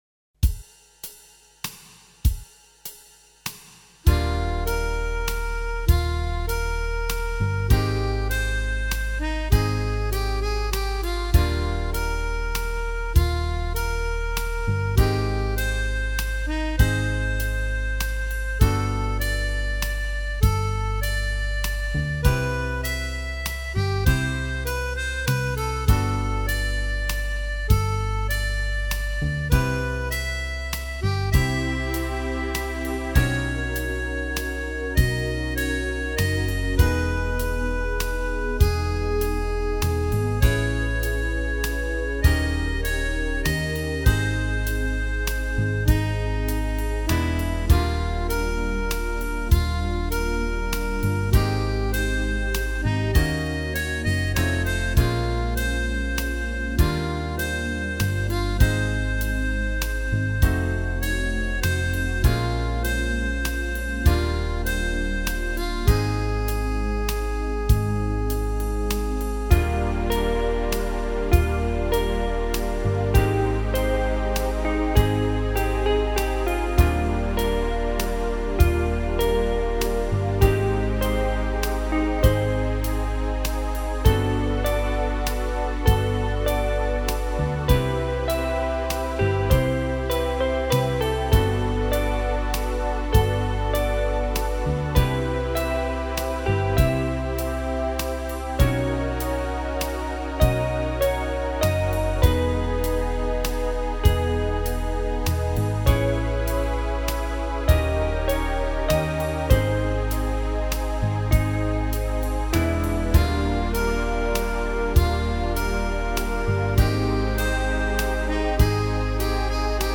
Enkla taktfasta